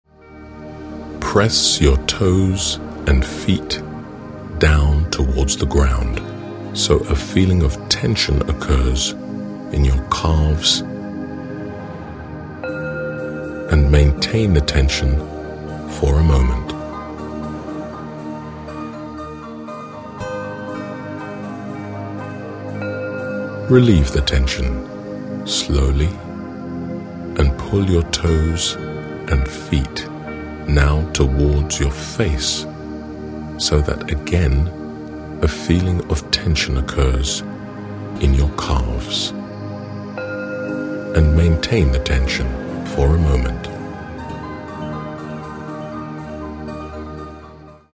This Audiobook is a guide for your self-studies and learning.